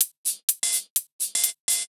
UHH_ElectroHatB_125-05.wav